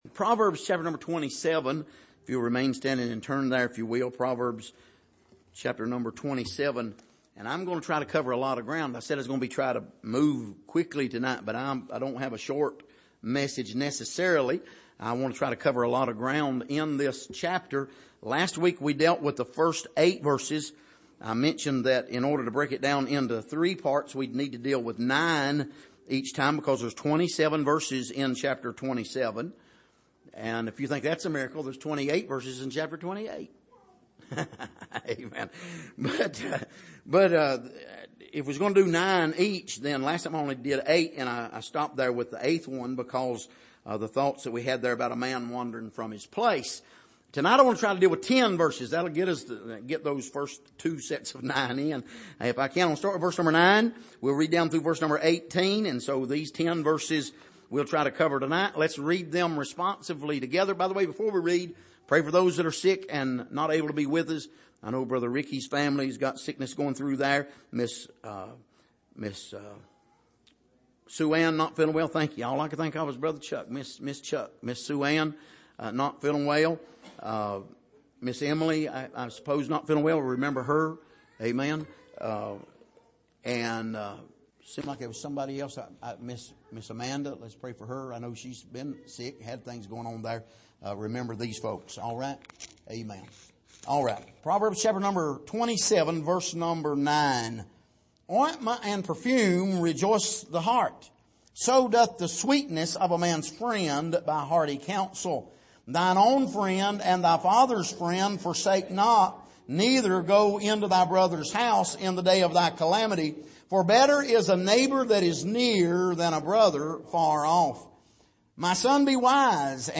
Passage: Proverbs 27:9-18 Service: Sunday Evening